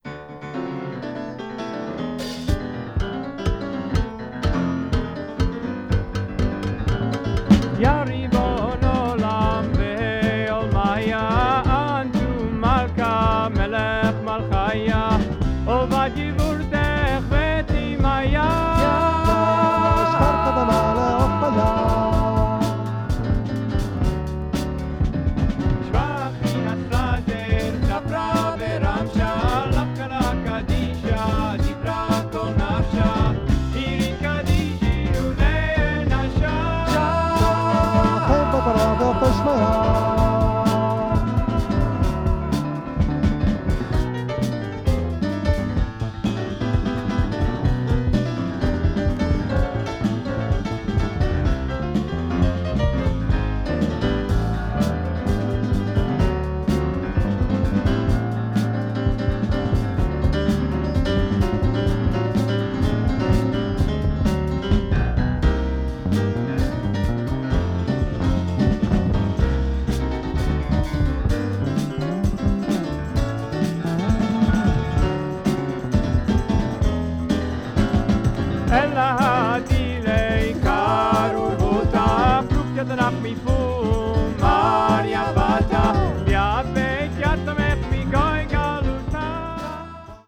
country   folk   funky rock   hebrew   psychedelic   r&b